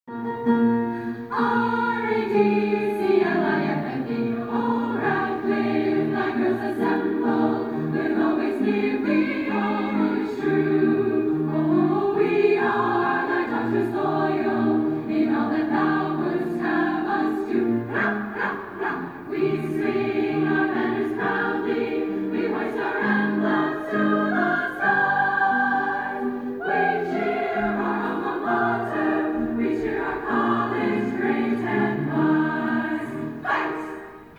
And of course the Radcliffe College fight song, R-A-D-C-L-I-F-F-E. Here’s a clip of the fight song.